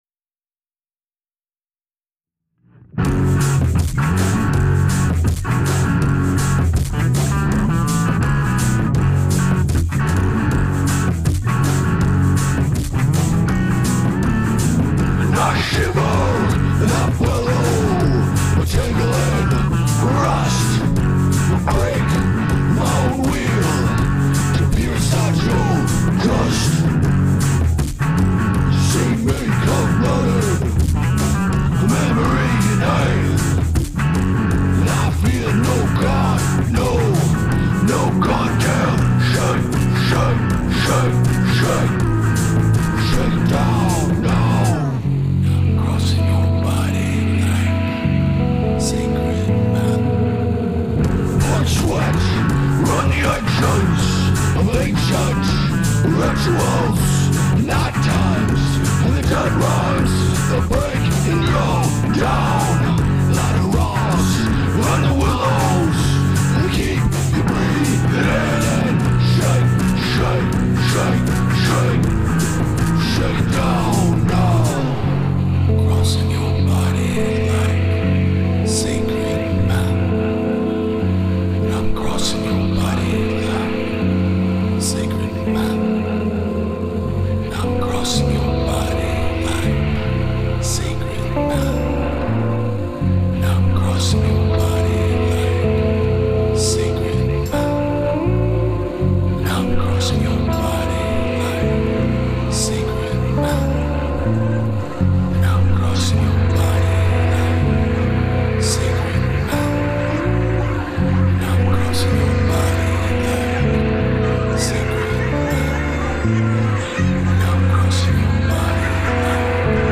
Typically moving somewhere in the vein of "Gothic Americana
evokes a sense of dark euphoria within their songs